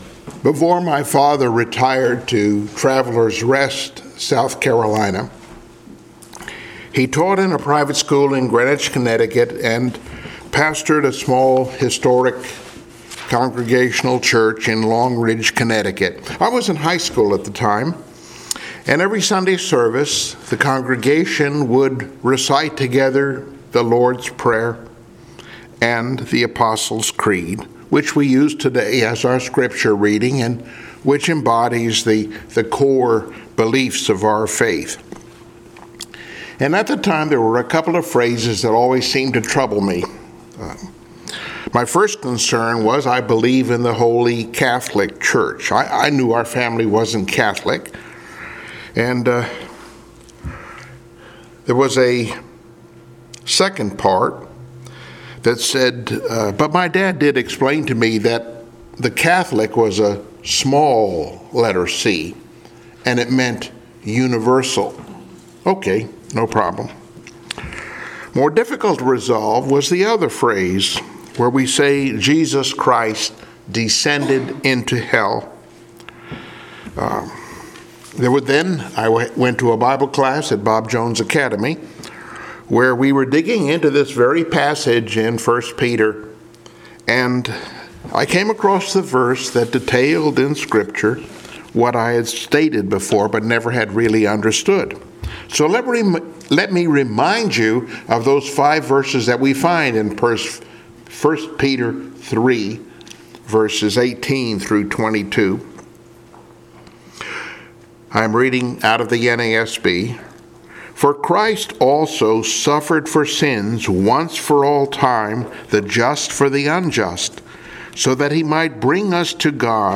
Passage: I Peter 3:18-22 Service Type: Sunday Morning Worship